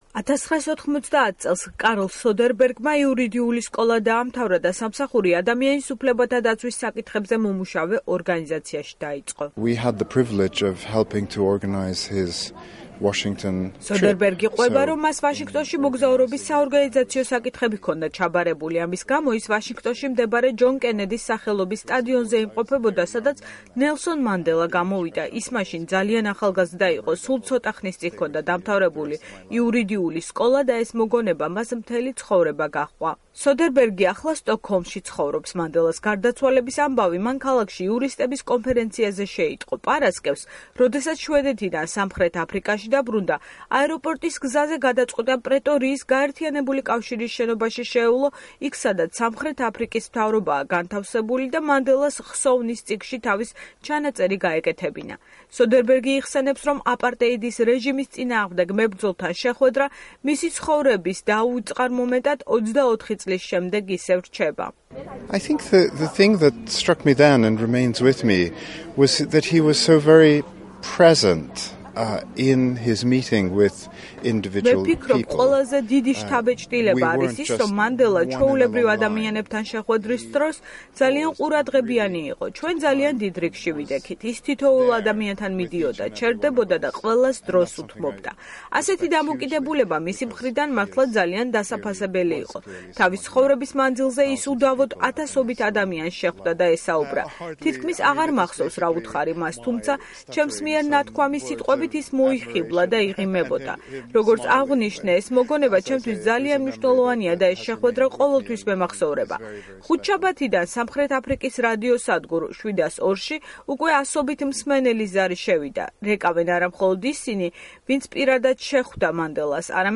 სამხრეთ აფრიკული რადიო სადგურების პირდაპირ ეთერში მოსახლეობა რეკავს და მანდელას შესახებ მოგონებებს ყვება